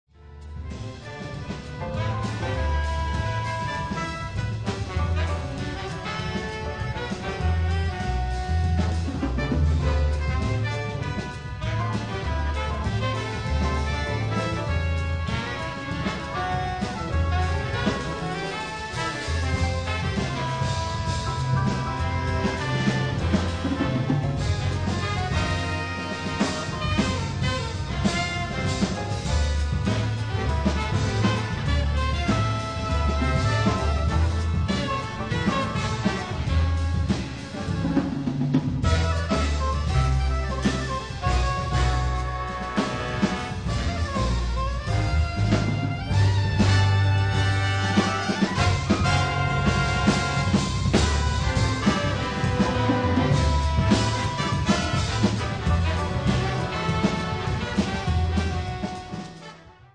trumpets
tuba
tenor sax, flute
tenor and soprano sax
baritone sax
piano
bass
drums
Recorded live at the Berklee Performance Center in Boston